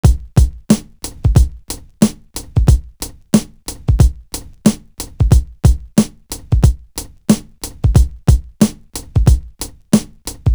Mobbngz Drum.wav